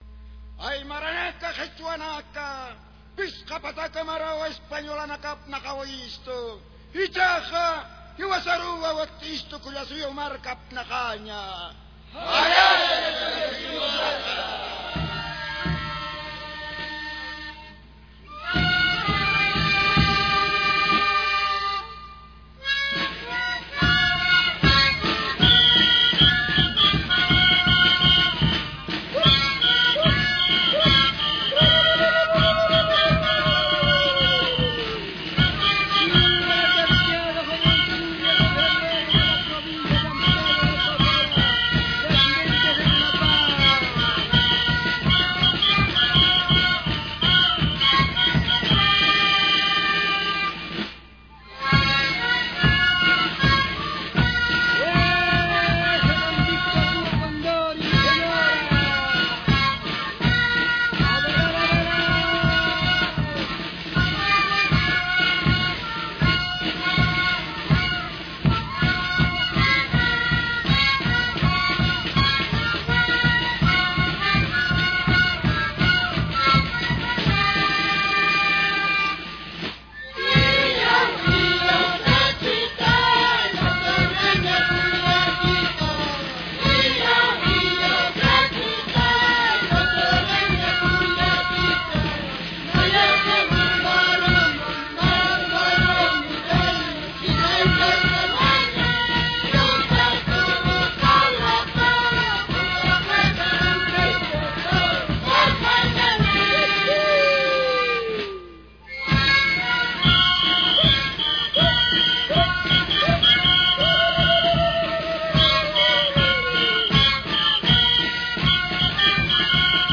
6.- Tarqueadas
Yunta Toro con copyright de Mario Mollo M Tarqueada 10 de Febrero de San Pedro de Totora, Oruro.